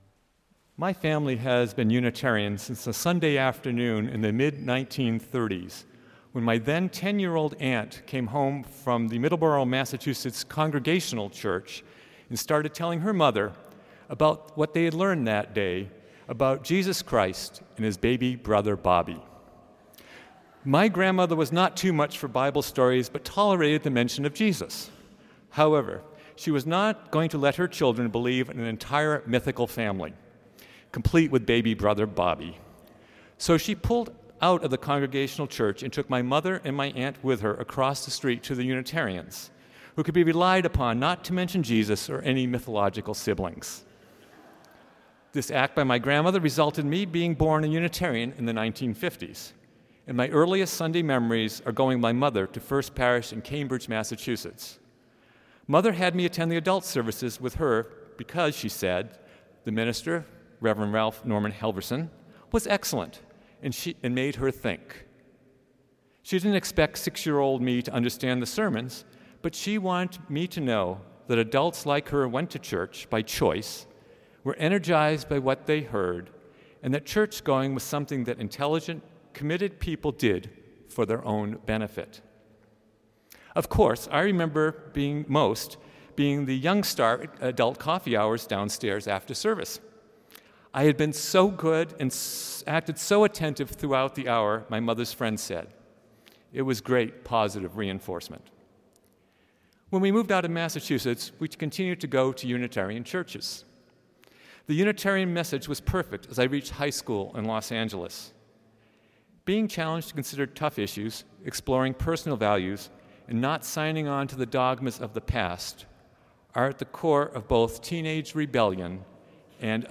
Delivered as a reflection at worship service of the First Unitarian Universalist Society of San Francisco on August 30, 2015.